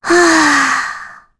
Rehartna-Vox_Sigh4_kr.wav